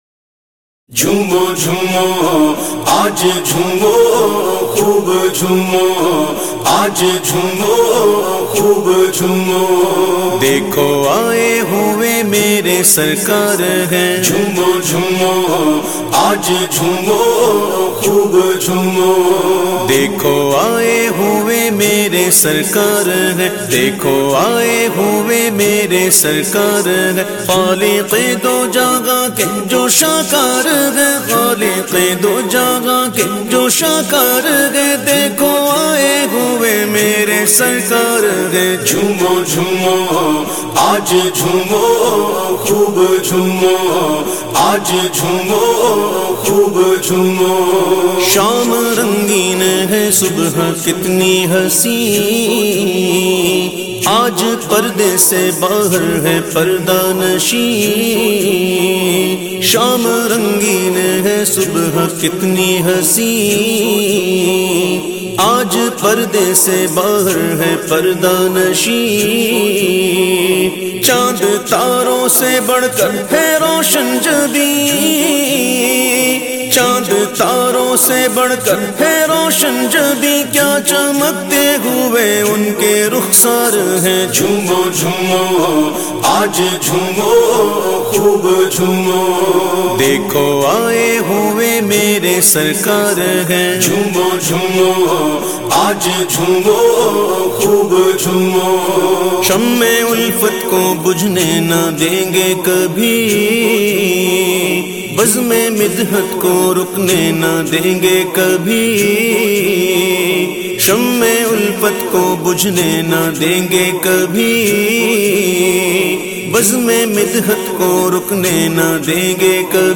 نعت